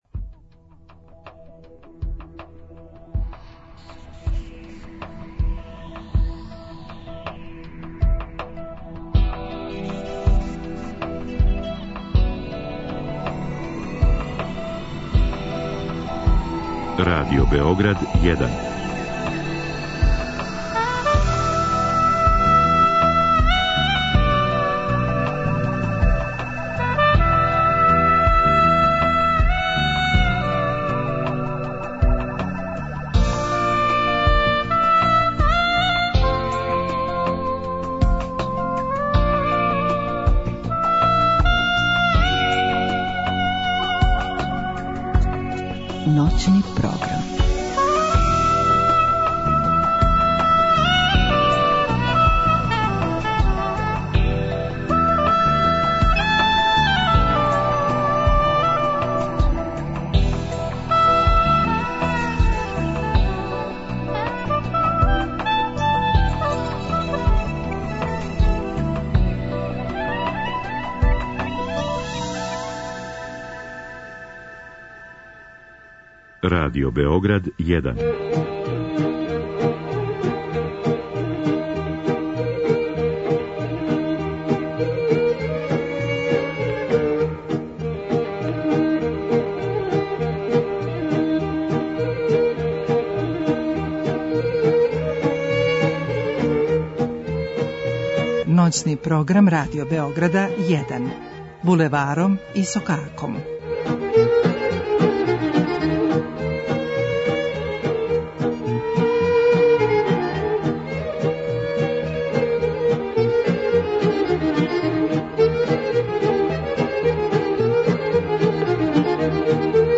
У пвом и другом сату слушамо музику центрлне и источне Србије.
У другом делу сата, слушаоци ће уживати у најлепшим пемама са Косова чији су певачи својом интерпретацијом поставили основе начина певања тог поднебља.